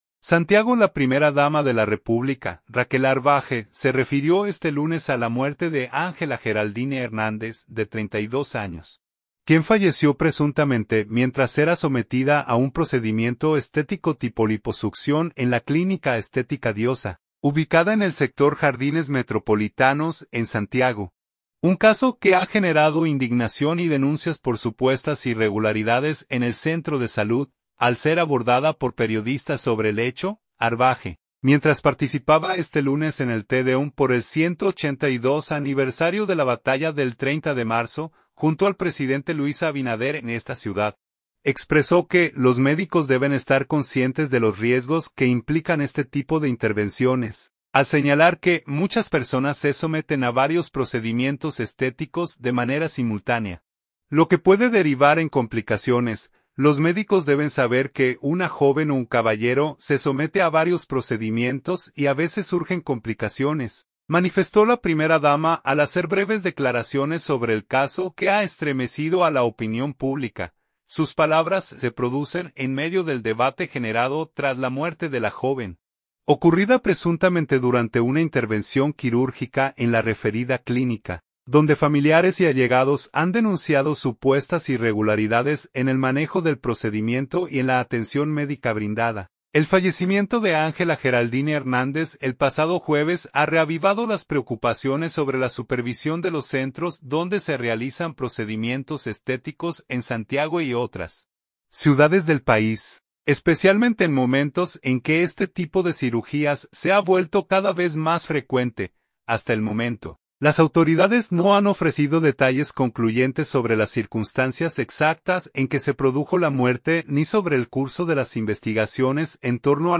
Al ser abordada por periodistas sobre el hecho, Arbaje, mientras participaba este lunes en el Tedeum por el 182 aniversario de la Batalla del 30 de Marzo, junto al presidente Luis Abinader en esta ciudad, expresó que los médicos deben estar conscientes de los riesgos que implican este tipo de intervenciones, al señalar que muchas personas se someten a varios procedimientos estéticos de manera simultánea, lo que puede derivar en complicaciones.
“Los médicos deben saber que una joven o un caballero se somete a varios procedimientos y a veces surgen complicaciones”, manifestó la primera dama al ofrecer breves declaraciones sobre el caso que ha estremecido a la opinión pública.